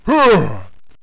warrior_attack4.wav